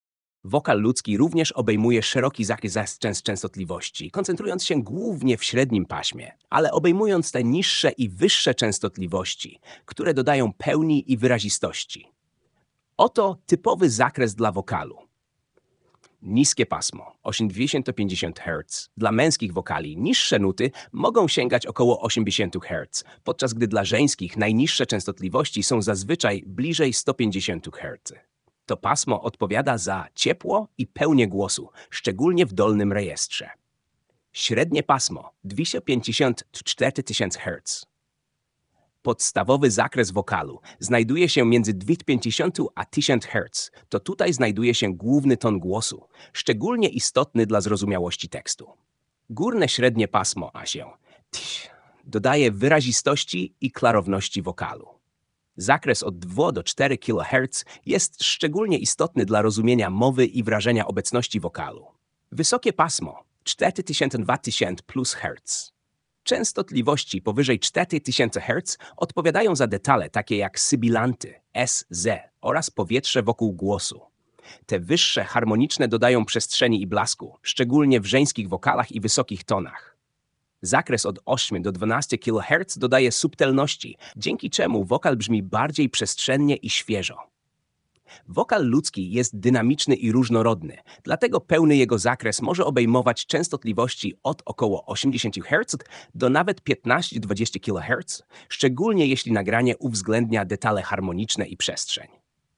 Lektor